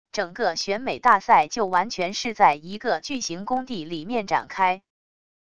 整个选美大赛就完全是在一个巨型工地里面展开wav音频生成系统WAV Audio Player